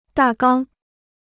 大纲 (dà gāng): synopsis; outline.